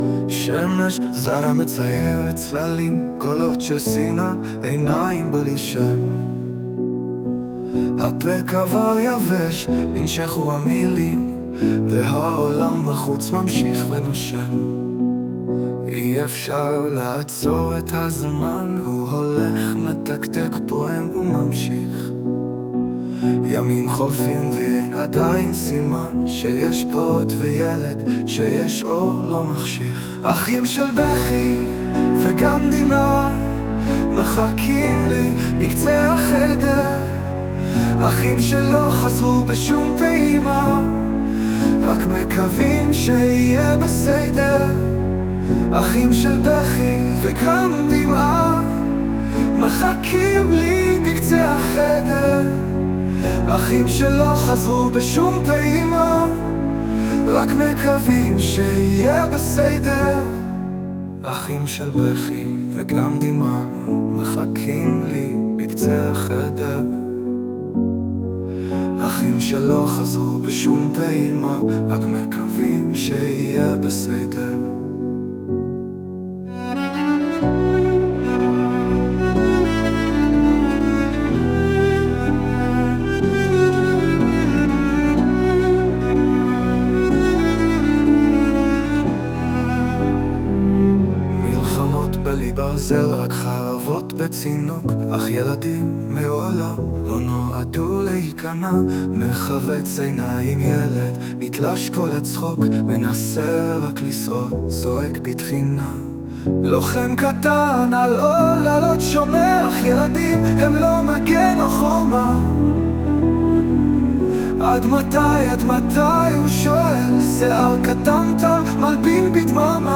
ישנם חסרונות רבים היכולים להיגרם ע"י שירת רובוט.
לפעמים השיר בסדר, הלחן והעיבוד יפים, אבל משום מה, הוא החליט שהוא חוזר 3 פעמים על הפזמון.